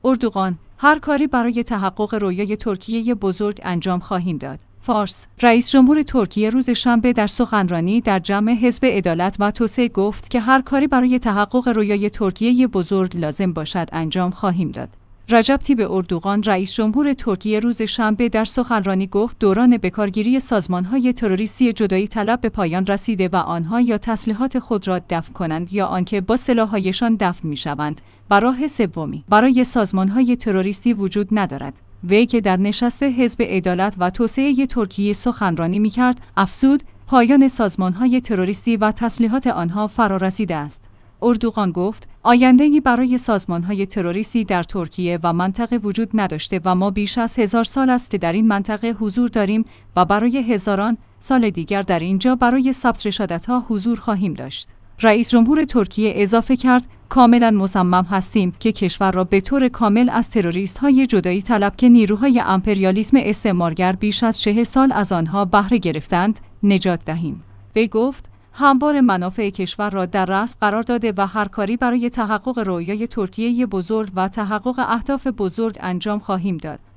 فارس/ رئیس جمهور ترکیه روز شنبه در سخنرانی در جمع حزب عدالت و توسعه گفت که هر کاری برای تحقق رؤیای ترکیه بزرگ لازم باشد انجام خواهیم داد.